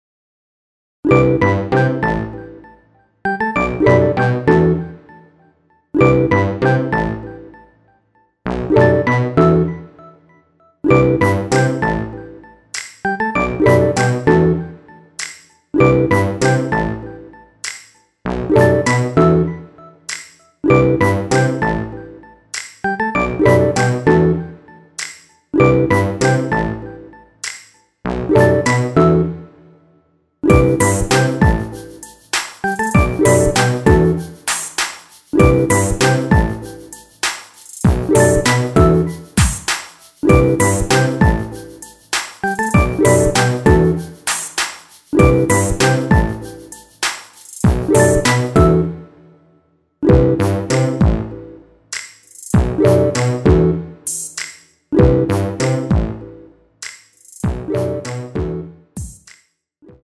Em
앞부분30초, 뒷부분30초씩 편집해서 올려 드리고 있습니다.